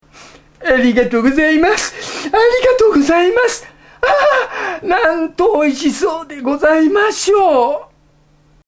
楳図かずおが、己の作品のワンシーンに自らの声で命を吹き込むという企画、「UMEZZ VOICE!」第四弾がついに公開！！
今回は前回に続き、食料を届けにきた少年へロッカーの中からこれ以上は無いと言うほどの感謝の言葉をかけるシーンです。
関谷氏の名演技、そしてそれがどんな名調子であったかを、楳図自身の声で確認してみましょう！